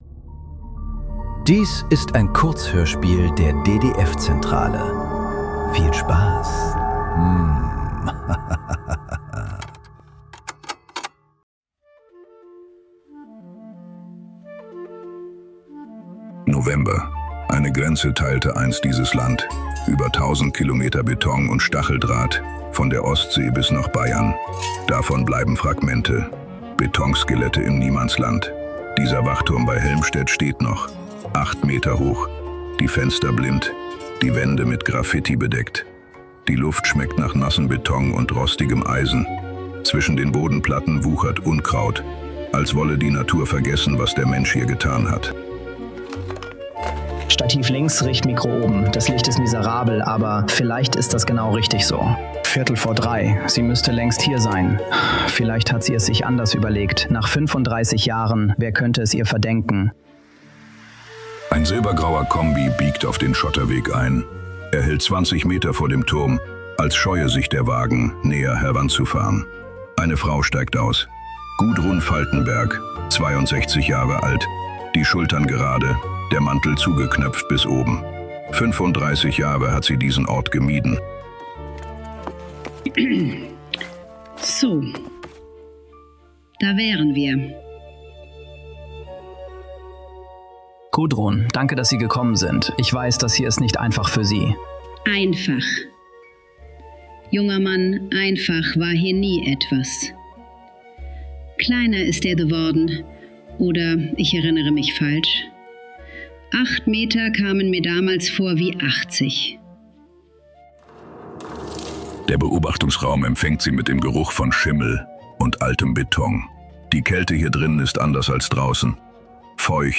Der Warnschuss ~ Nachklang. Kurzhörspiele.